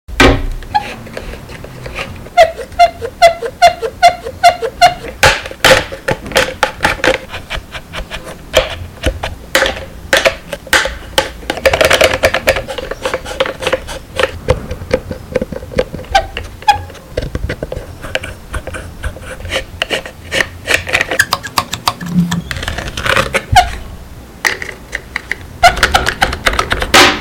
ASMR 🦖 Indominus Rex! Jurassic sound effects free download